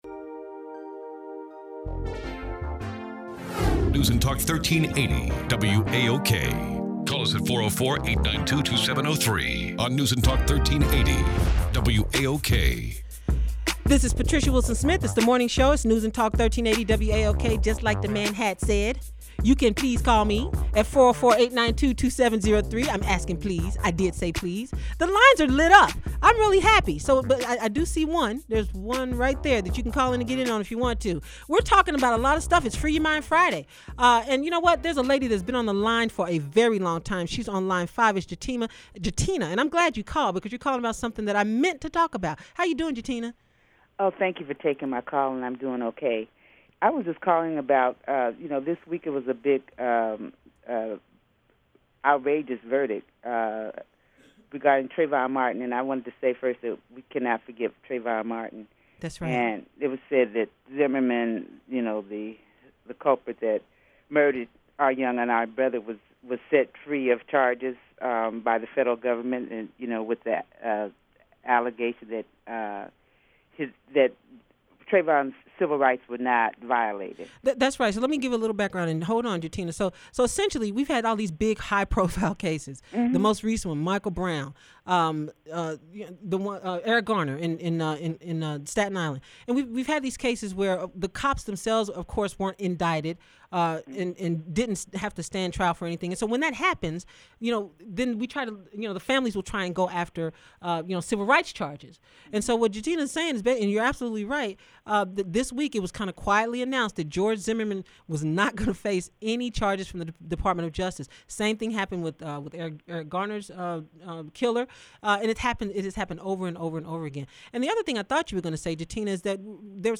But it was a fun interview to do, complete with a break in the middle for a call-in guest who propounded the notion that Barack Obama was a warlock.
Part 1 and Part 2) which contain the interview.